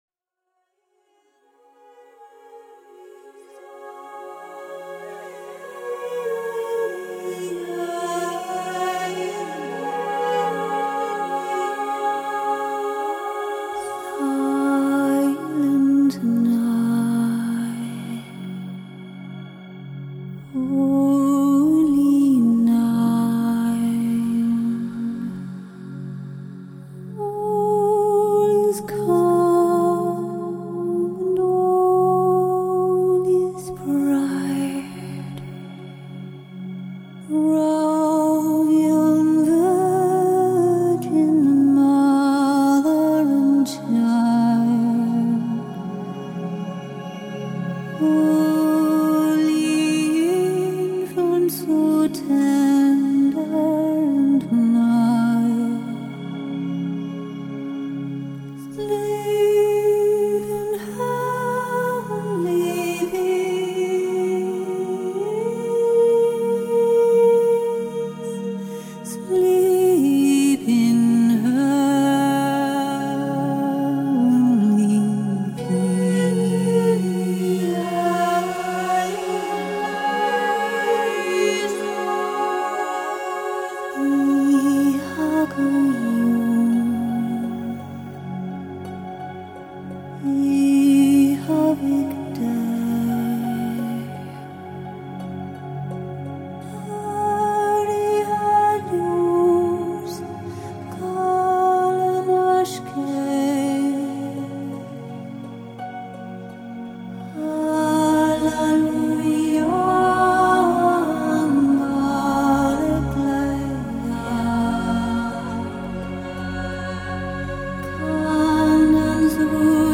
ethereal otherwordly arrangement
traditional carol